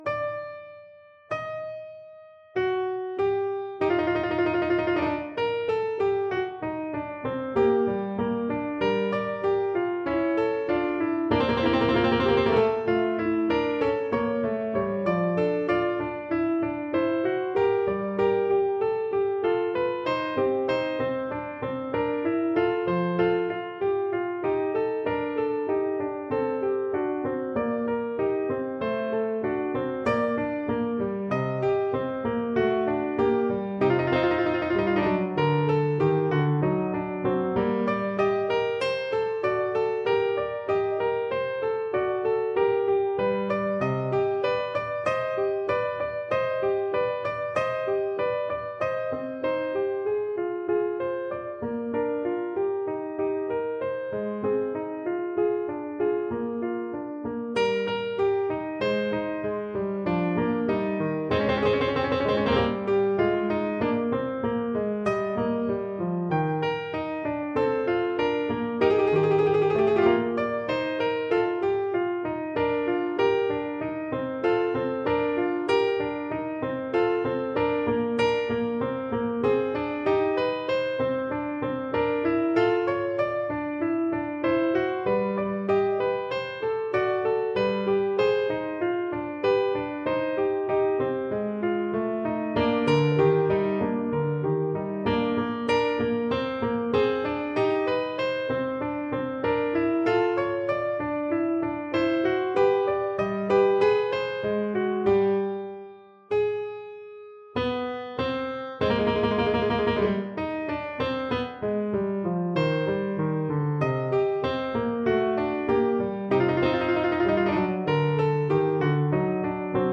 This sheet music is arranged for Violin and Viola.
» 442Hz